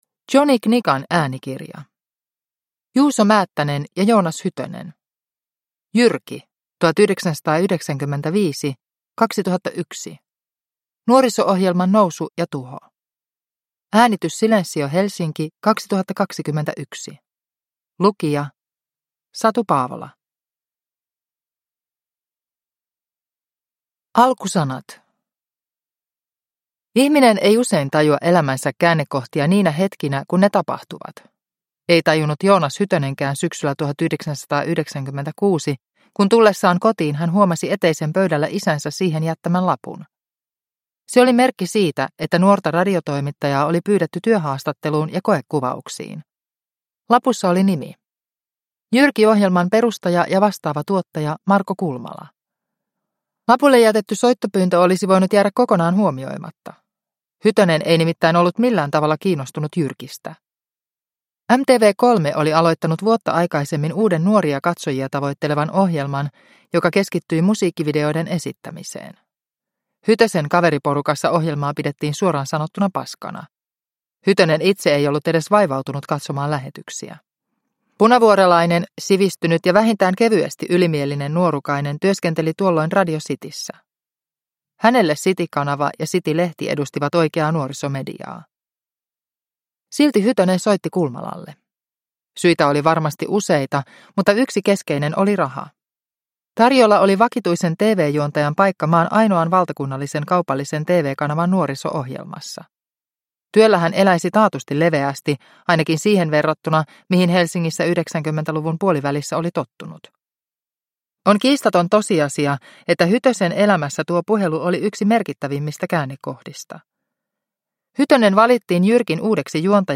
Jyrki 1995–2001 – Ljudbok – Laddas ner